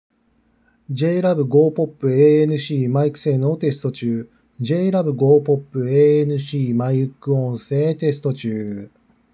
✅JLab Go Pop ANCのマイク性能
多少のこもりはあるが値段相応のマイク性能はあります。